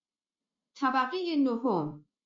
جلوه های صوتی
برچسب: دانلود آهنگ های افکت صوتی اشیاء دانلود آلبوم صدای اعلام طبقات آسانسور از افکت صوتی اشیاء